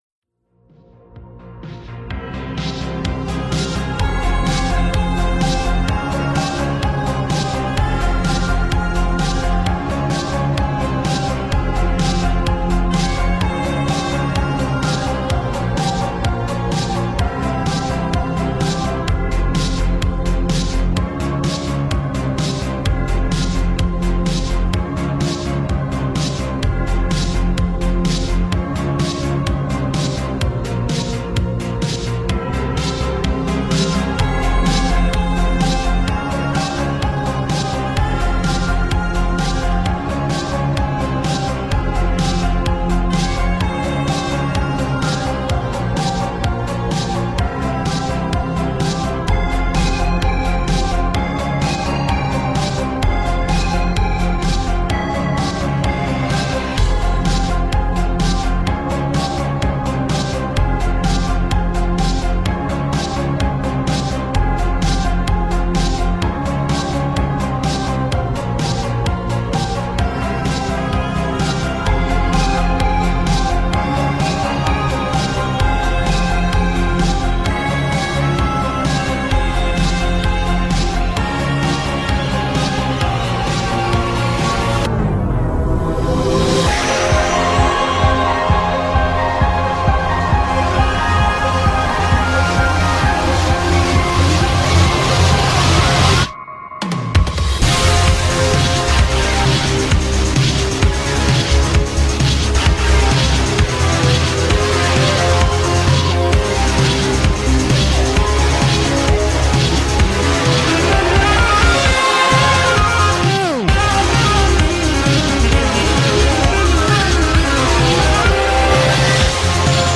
music that makes me feel sadness